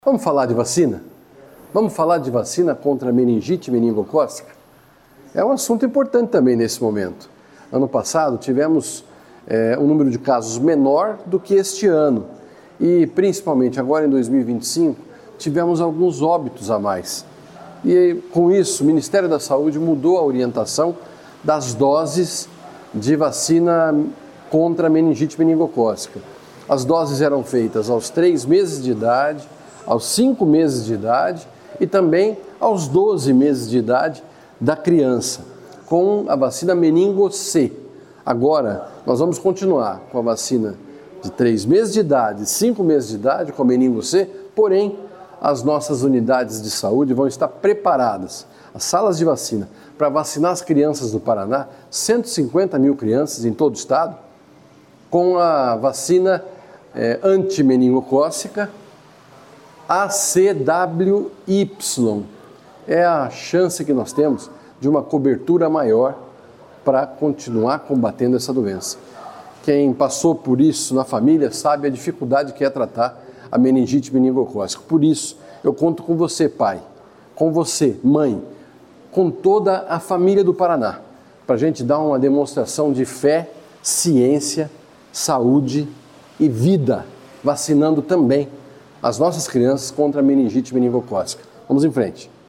Sonora do secretário da Saúde, Beto Preto, sobre a vacinação e diagnóstico contra doença meningocócica